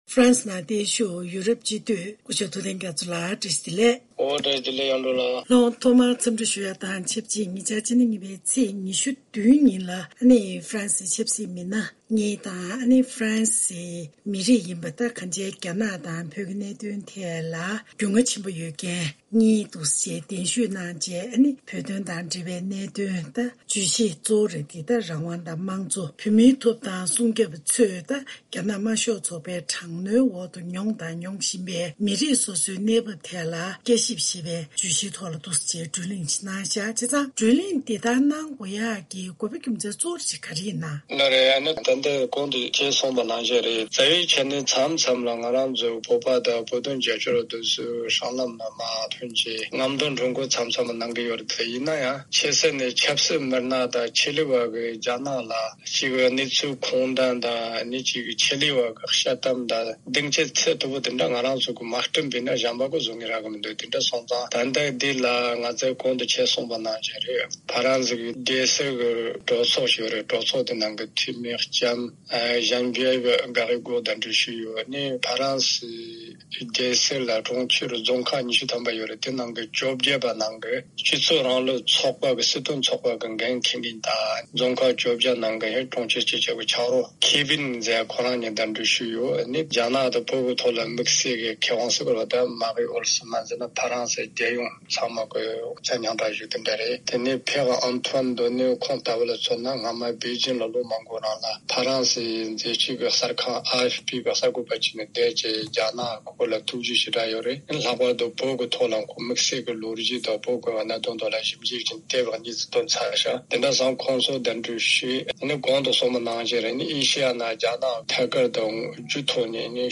གནས་འདྲིའི་ལེ་ཚན་ནང།